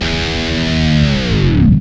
Index of /90_sSampleCDs/Roland L-CD701/GTR_GTR FX/GTR_E.Guitar FX